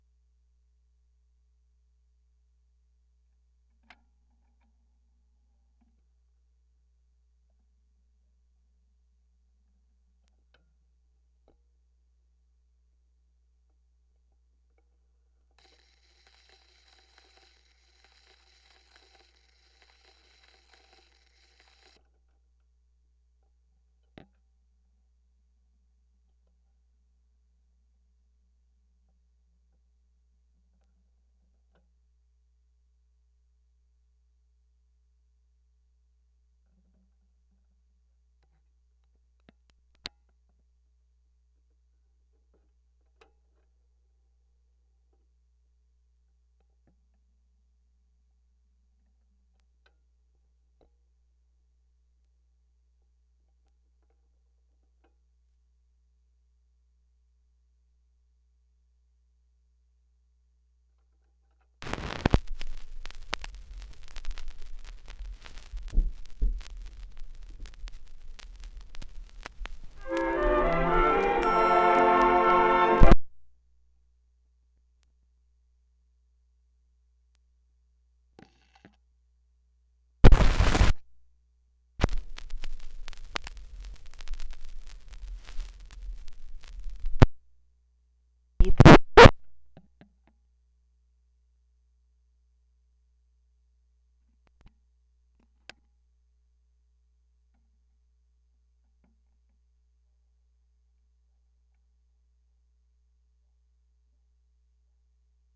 various sounds of a record player
music noise record sound effect free sound royalty free Music